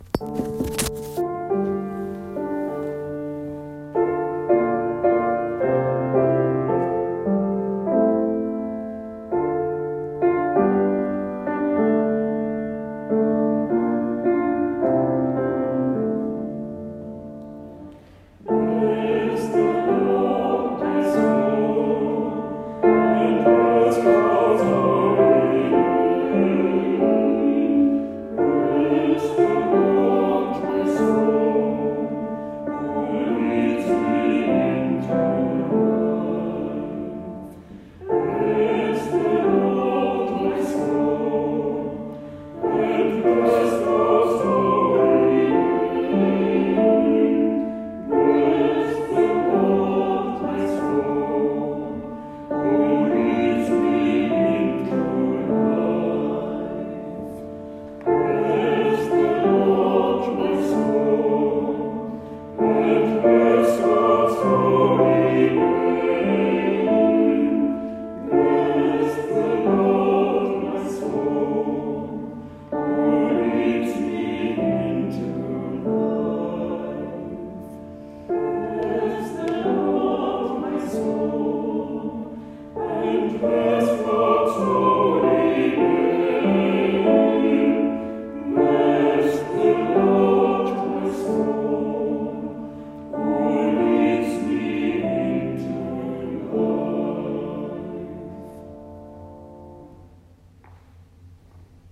🌟 Was ist eine Taizé-Andacht?
Die Musik wirkt beruhigend und verbindend.